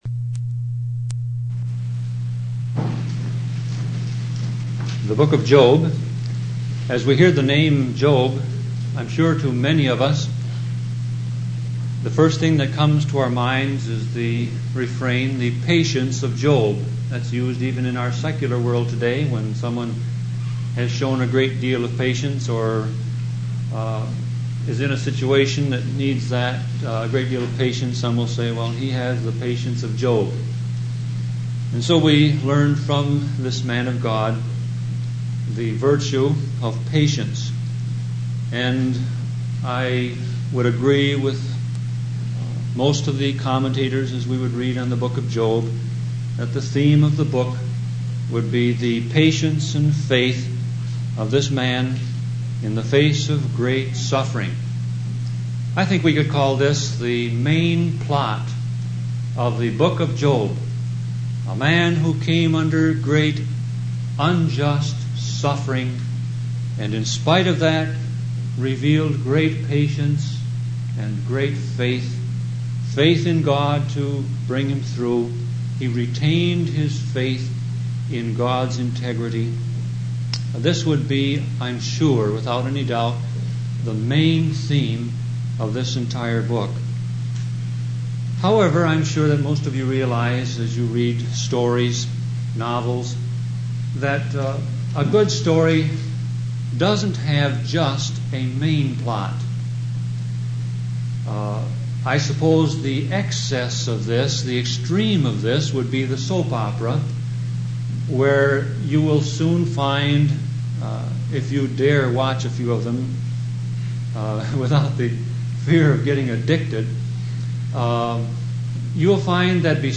Sermon Audio Passage: Job 1 Service Type